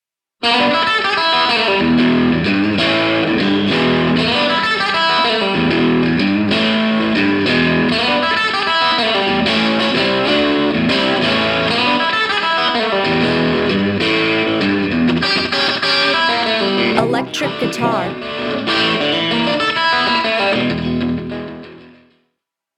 Guitarra ELÉCTRICA
O único que precisa para funcionar é electricidade, xa que como non ten caixa de resonancia emprega un sistema de micrófonos que recollen o son producido nas cordas de metal e a través do cable o envía ata un altofalante (ou "ampli" para os amigos).
guitarraelectrica.mp3